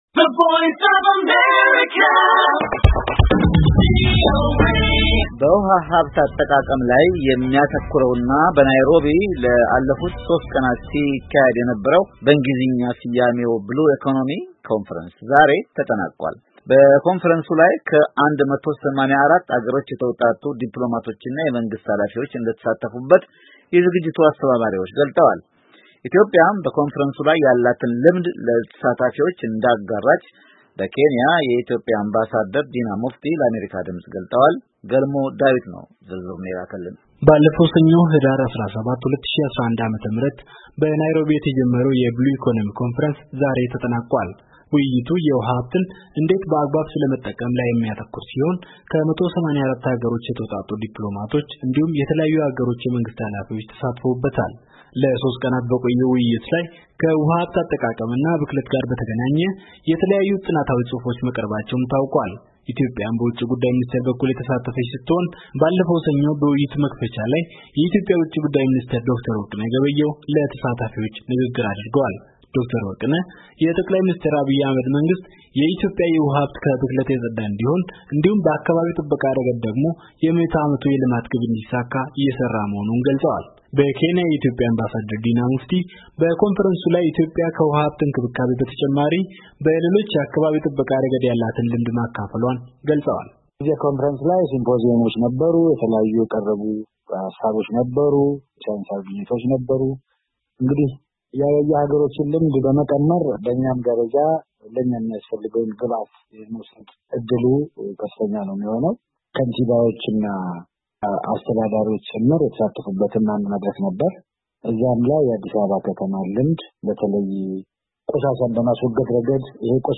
ኢትዮጵያም በኮንፍረንሱ ላይ ያላትን ልምድ ለተሳታፊዎች እንዳጋራች በኬንያ የኢትዮጵያ አምባሳደር ዲና ሙፍቲ ለአሜርካ ድምፅ ገልፀዋል።